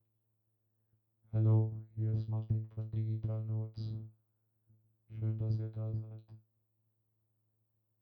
Der Vocoder:
Bei den Klangbeispielen habe ich einmal meine Stimme “normal” aufgenommen und dann 2 Beispiele mit dem Vocoder daraus gemacht.
so6-vocoder-1.mp3